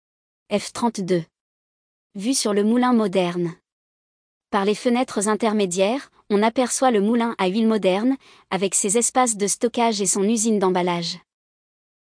Visite guidée audio